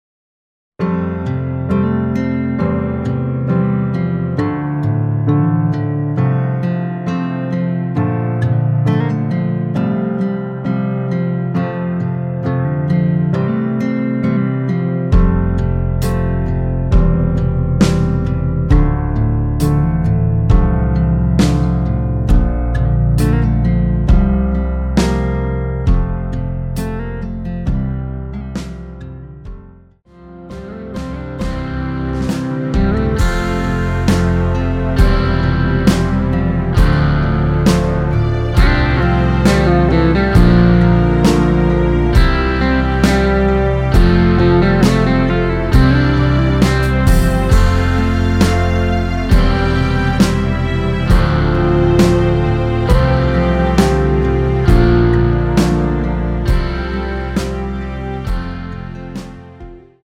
원키에서(-3) 내린 MR 입니다.(미리듣기 참조)
앞부분30초, 뒷부분30초씩 편집해서 올려 드리고 있습니다.
중간에 음이 끈어지고 다시 나오는 이유는